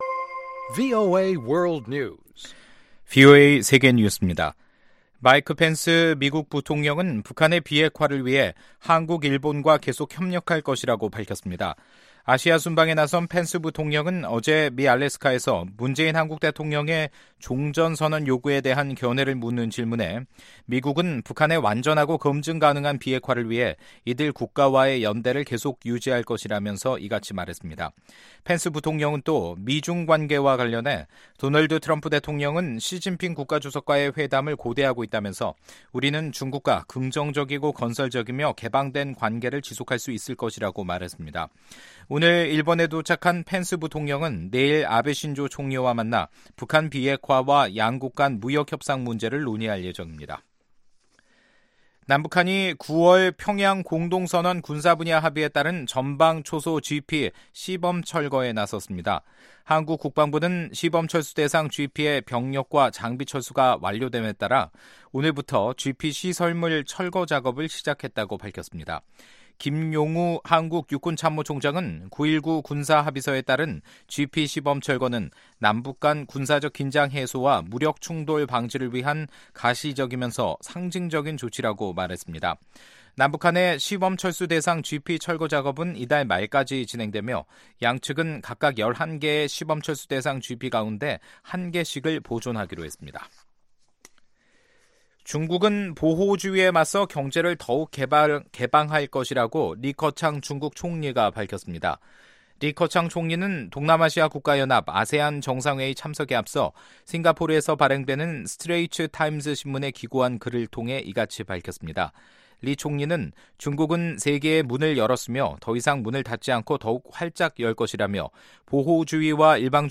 VOA 한국어 간판 뉴스 프로그램 '뉴스 투데이', 2018년 11월 12일 3부 방송입니다. 미국과 중국이 워싱턴에서 2차 미-중 외교안보대화를 열고 북한의 비핵화 문제를 포함한 현안과 관계 강화 방안에 대해 논의했습니다. 북한과의 협상을 서두르지 않겠다는 트럼프 대통령의 입장이 북한 문제를 더욱 악화시킬 수 있다는 주장이 나왔습니다.